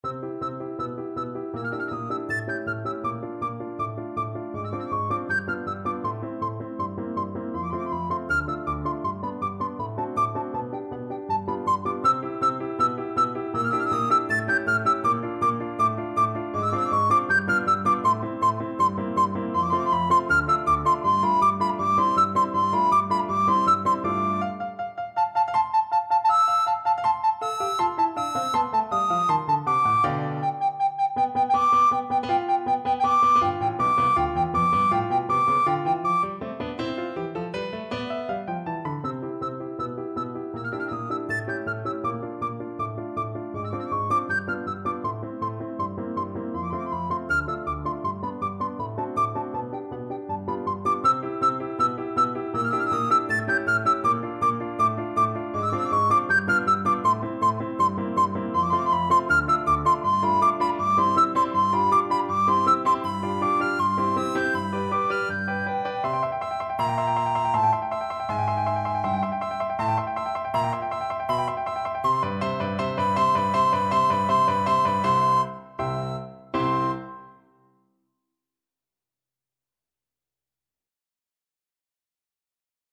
Classical Saint-Saëns, Camille Carnival of the Animals - Finale Soprano (Descant) Recorder version
Recorder
C major (Sounding Pitch) (View more C major Music for Recorder )
Molto Allegro = c. 160 (View more music marked Allegro)
4/4 (View more 4/4 Music)
F#6-A7
Classical (View more Classical Recorder Music)